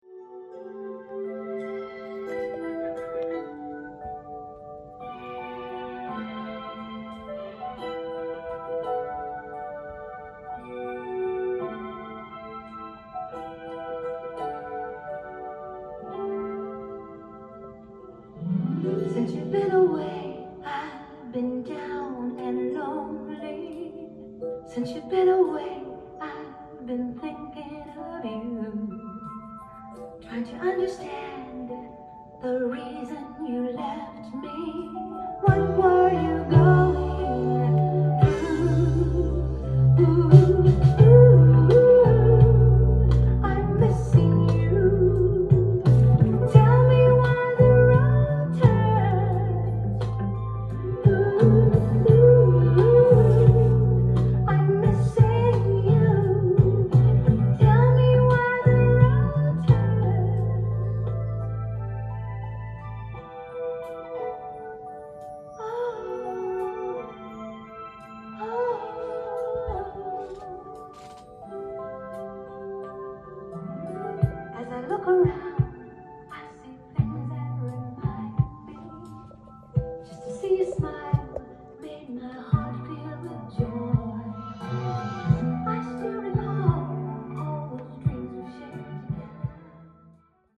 ジャンル：SOUL-SALE
店頭で録音した音源の為、多少の外部音や音質の悪さはございますが、サンプルとしてご視聴ください。